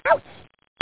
ouch.mp3